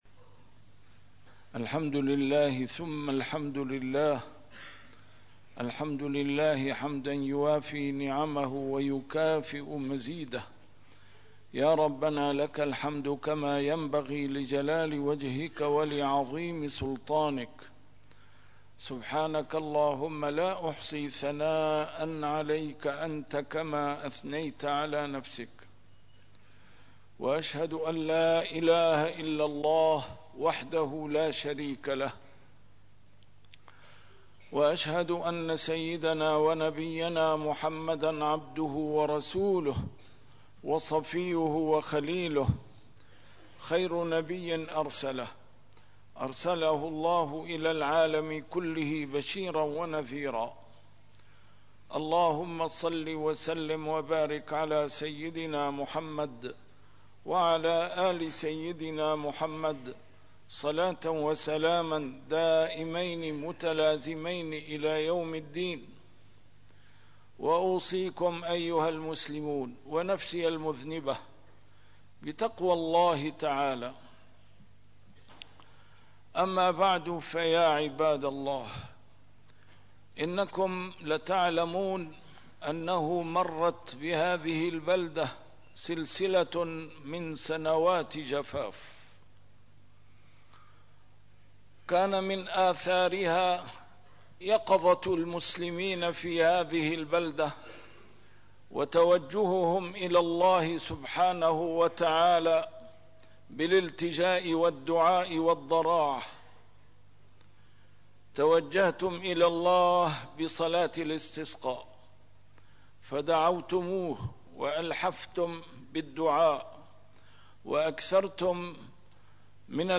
A MARTYR SCHOLAR: IMAM MUHAMMAD SAEED RAMADAN AL-BOUTI - الخطب - حصِّنوا النعم بشكر الله